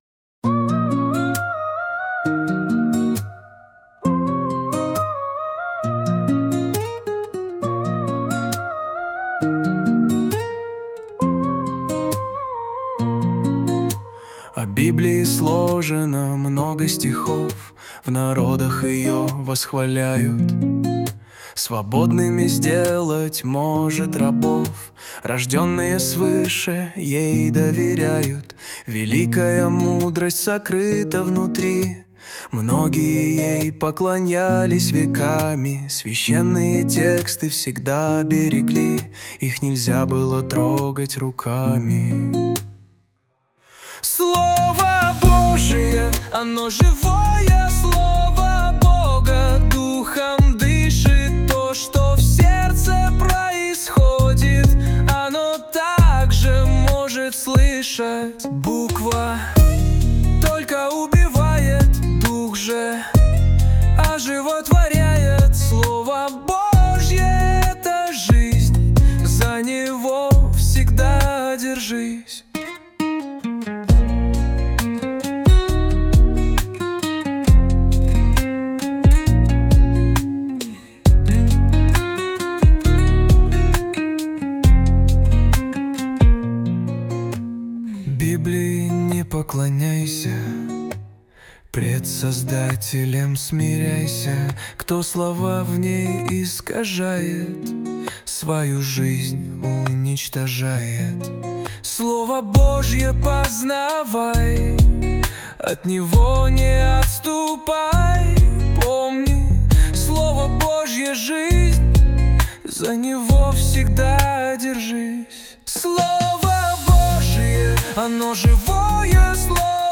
песня ai
227 просмотров 1005 прослушиваний 55 скачиваний BPM: 68